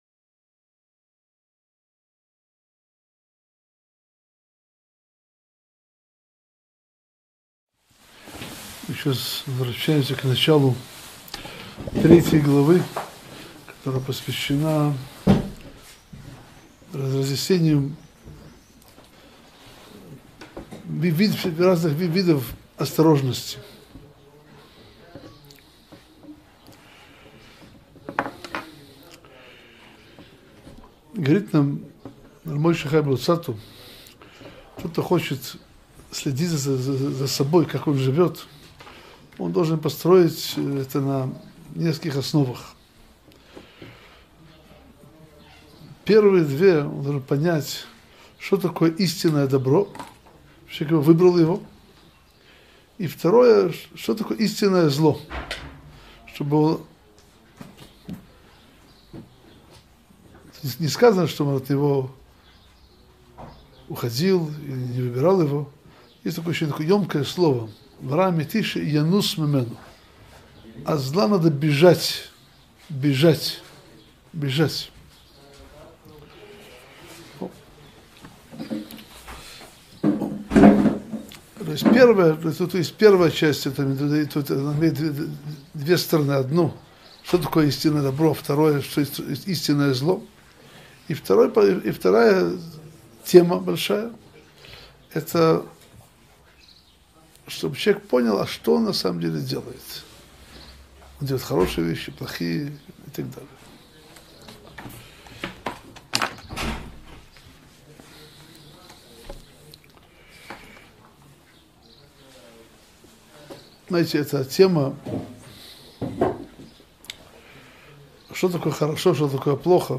Месилат Йешарим – Урок 86 - Корень добра и зла - Сайт о Торе, иудаизме и евреях